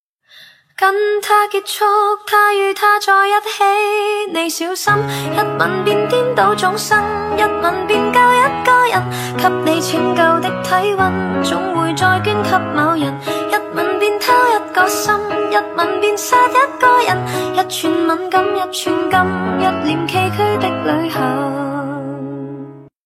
поп
женский вокал
спокойные
пианино